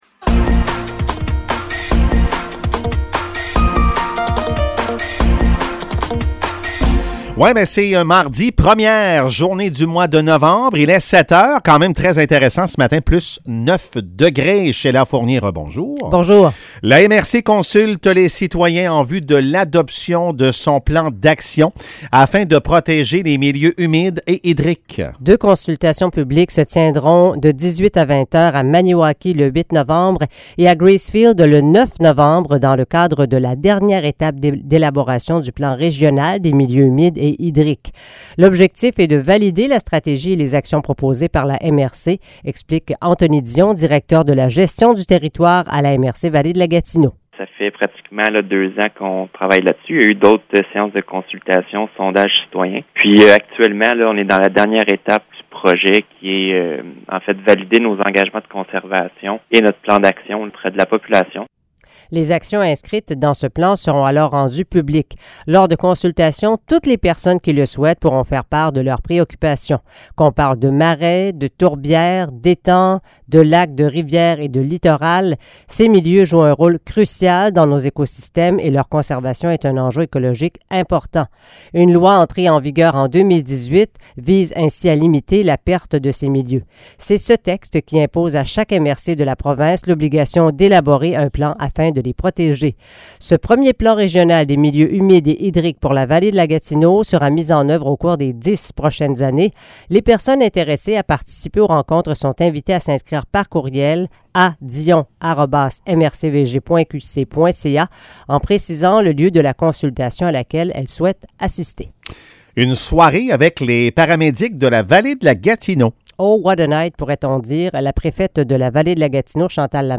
Nouvelles locales - 1er novembre 2022 - 7 h